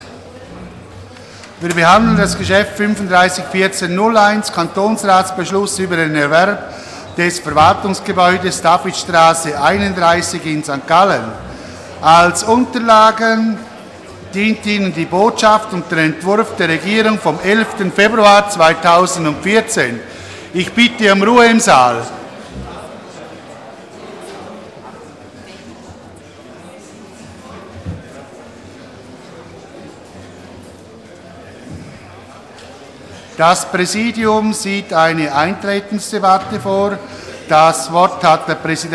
Session des Kantonsrates vom 2. bis 4. Juni 2014